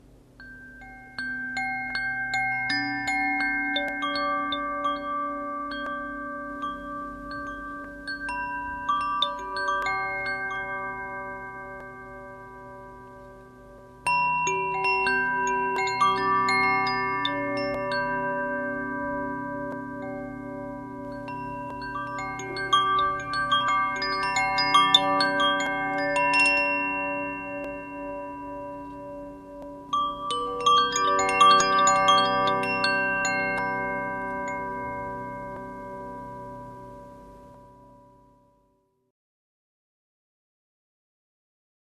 Vyrobena z kvalitního bambusu a kovu, vytváří uklidňující, harmonické tóny ideální pro relaxaci, meditaci a zvukovou terapii.
• Ignis (Oheň): G B D G B D G (pentatonická)
Každá zvonkohra má osm tónů a vyznačuje se magickým timbrem.
Jsou oblíbené pro svůj čistý, harmonický zvuk a schopnost vytvářet uklidňující zvukovou krajinu.
G dur (pentatonická)